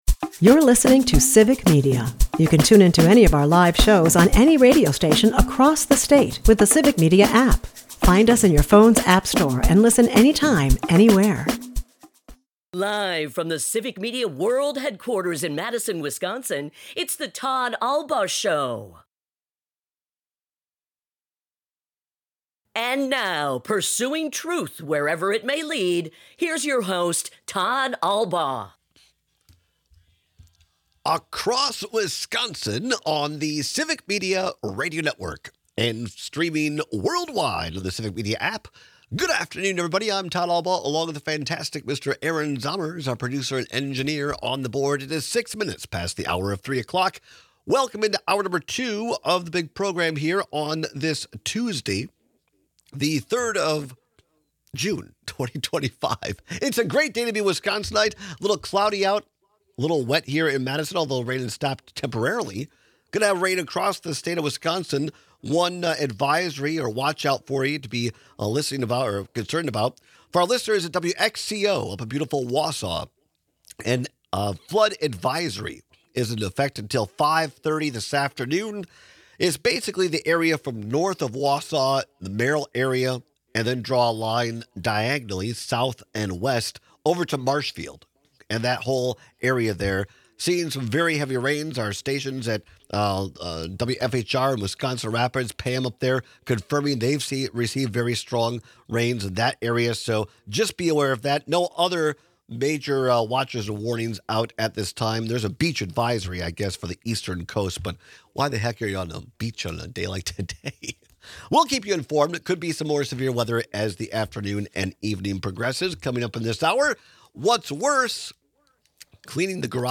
We take your calls and texts on which chore is more daunting.